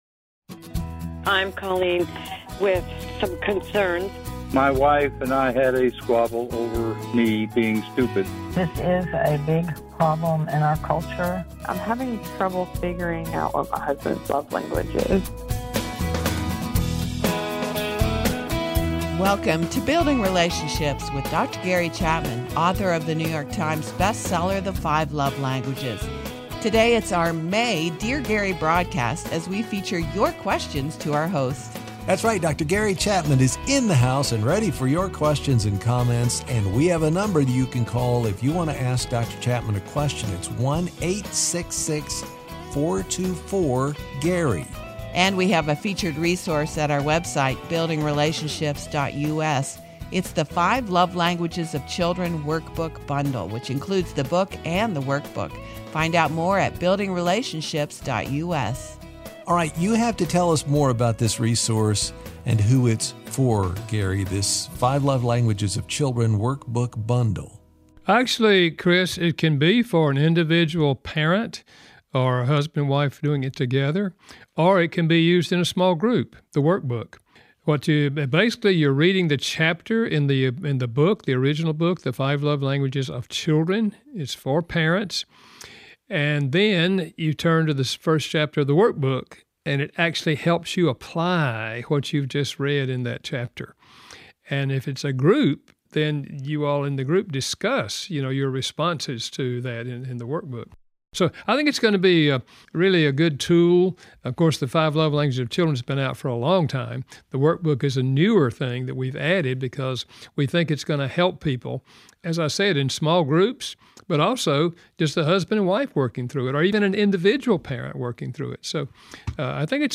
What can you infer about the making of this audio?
Once a month we open the lines for your questions for this trusted author of the New York Times Bestseller, the Five Love Languages.